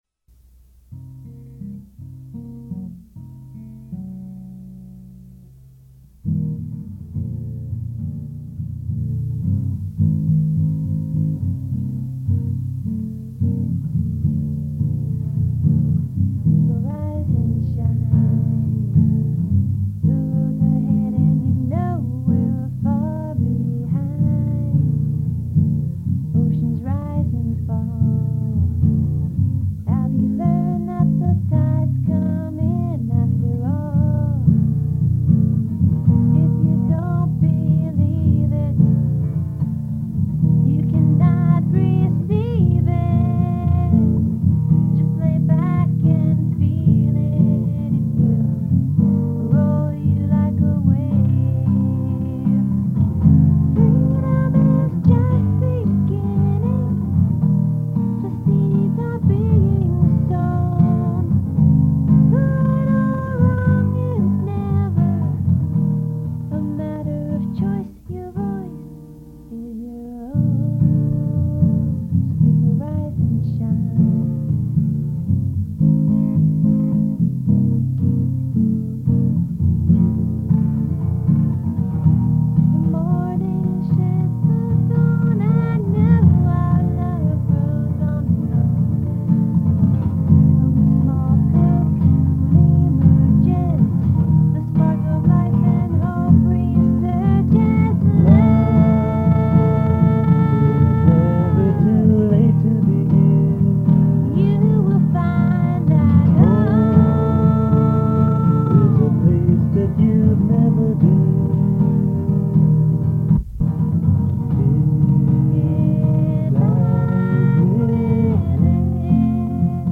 Practice Session - circa 1976